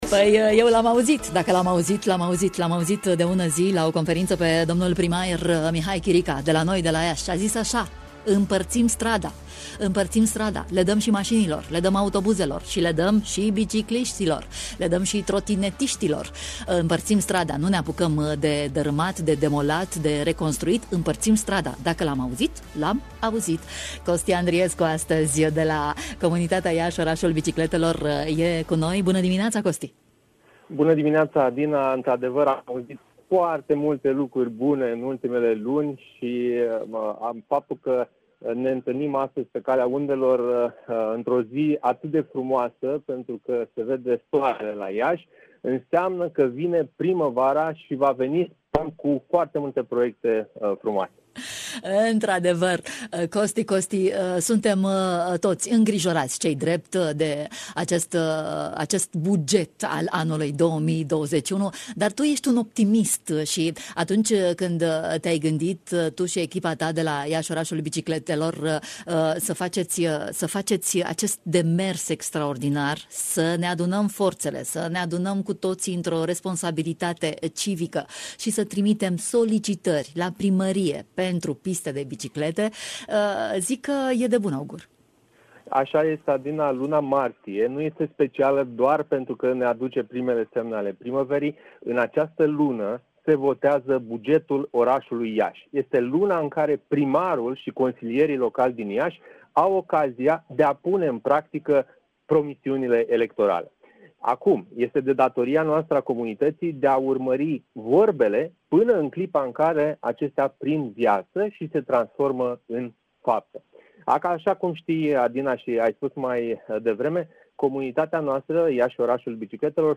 a stat de vorbă cu noi în matinal: